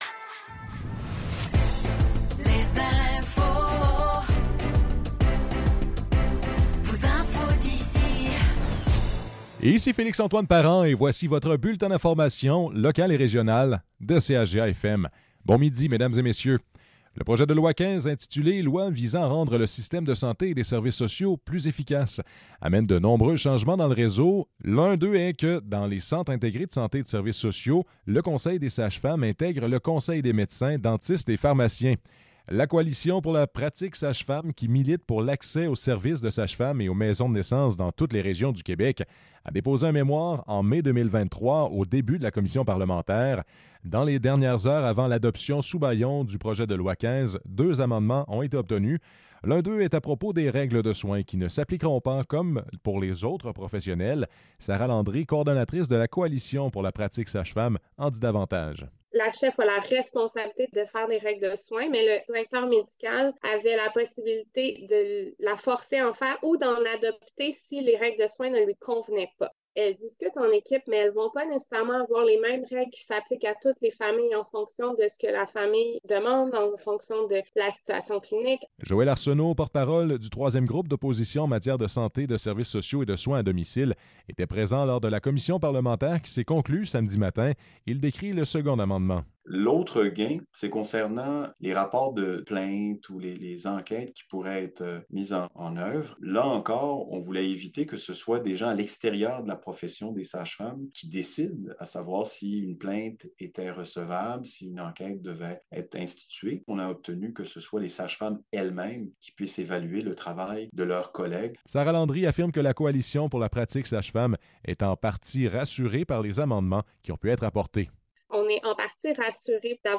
Nouvelles locales - 12 décembre 2023 - 12 h